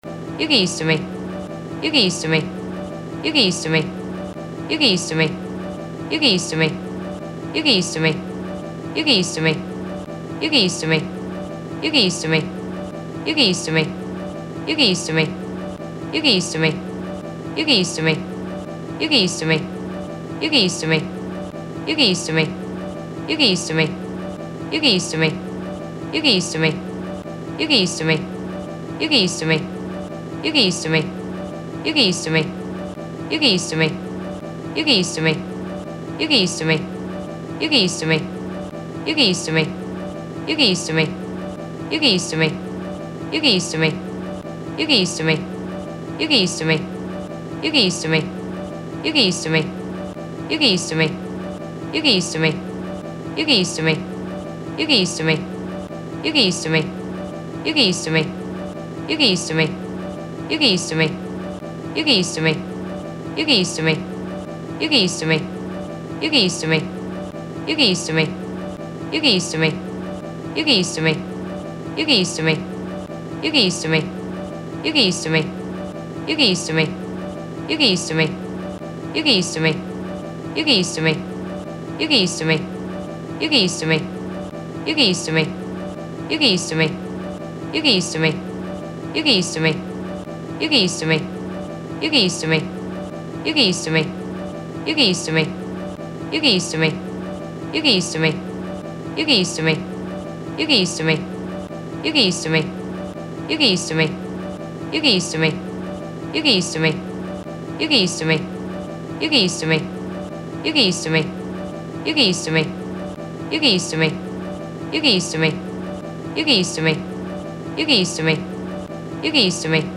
無限ループ音声を作りましたので、、
永遠とループしてますよね。